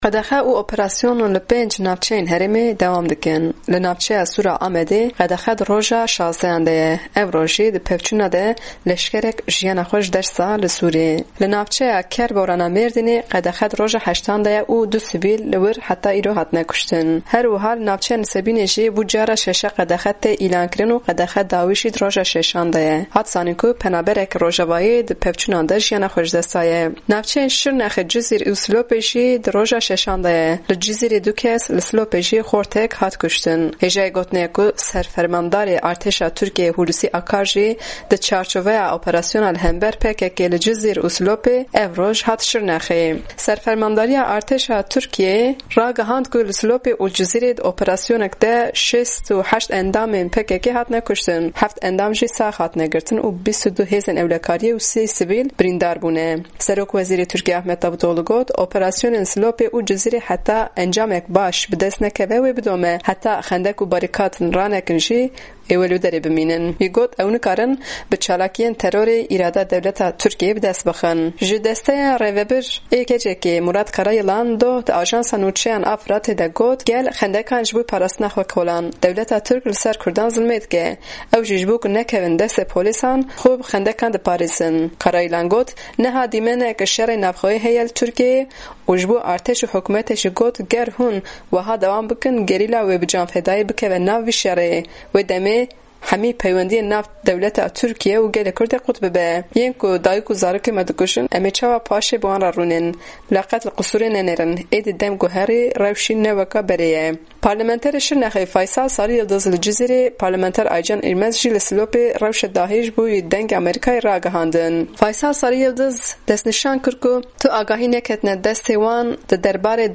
Parlamenetrê Şirnexê Faysal Sariyildiz ji Cizîrê, parlamentar Aycan irmaz jî ji Silopê rewşa dawî ragihandin jibo dengê Amerîka.
Raport bi Deng 12_19_15